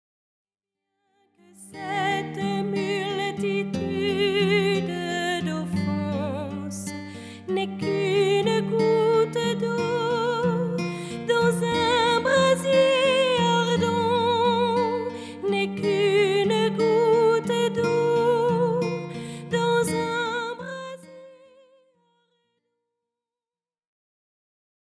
Cinq dizaines tirées des différents mystères du rosaire, entrecoupées de citations et de chants pour nourrir la prière.
Format :MP3 256Kbps Stéréo